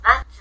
Plain Form / Casual
matsu